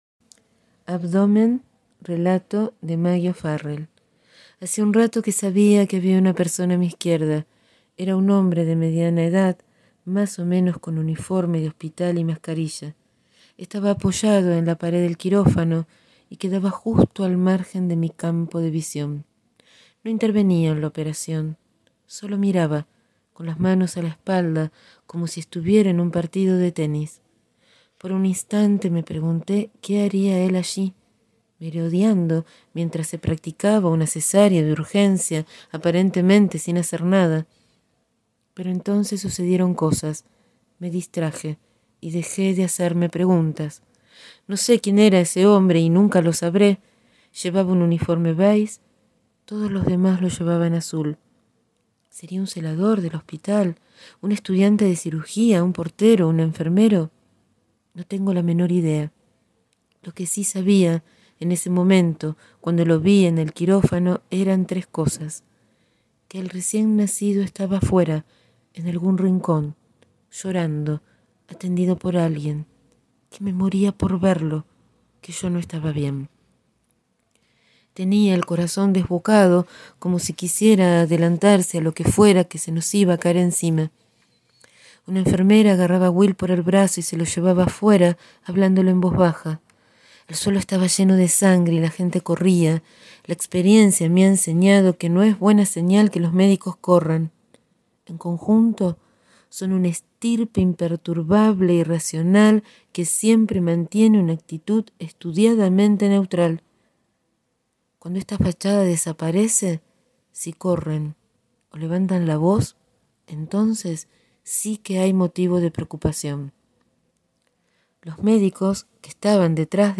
Hoy leo el relato «Abdomen» del libro «Sigo aquí» escrito por Maggie O´Farrell (Irlanda 1972).